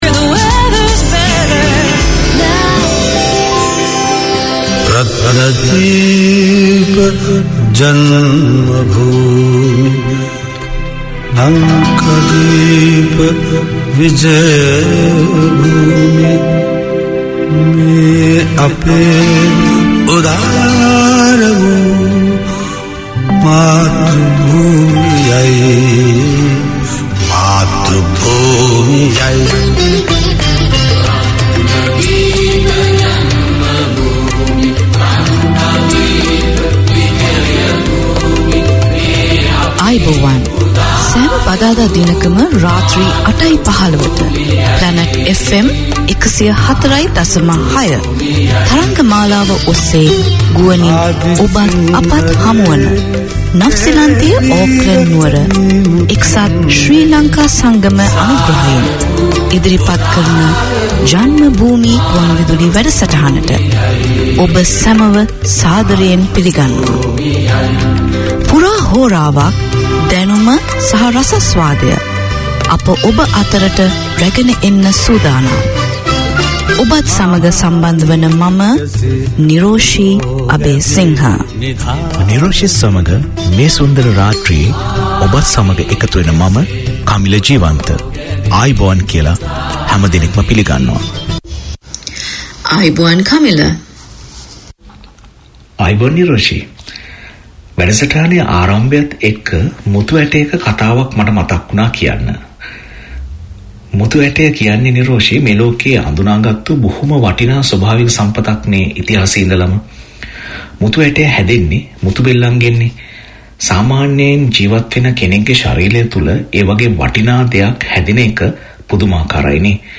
Community Access Radio in your language - available for download five minutes after broadcast.
A programme for Sri Lankan Kiwis in Auckland and beyond, Sriwi Hada offers practical advice on everything from legal issues, immigration, getting around the city, and everything you need to know for surviving and thriving in Aotearoa. All this, and great music!